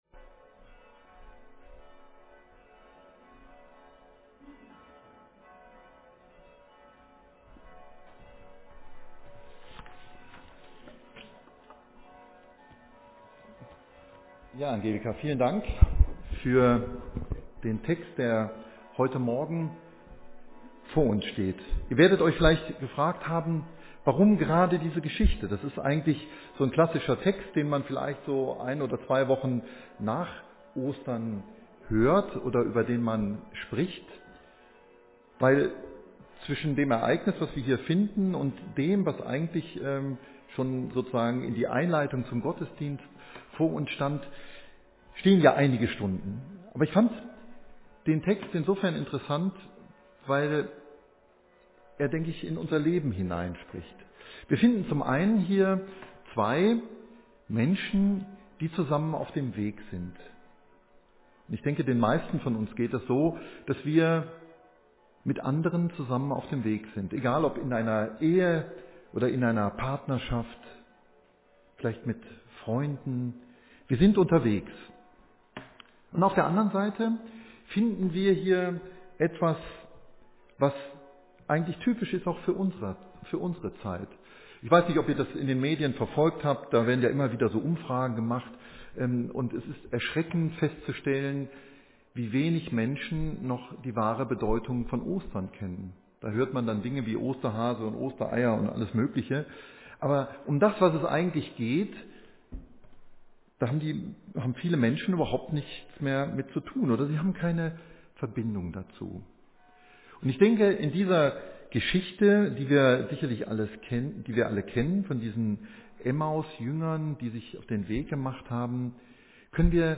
[NB: Video- und Liederbeiträge im Vortrag weggeschnitten.]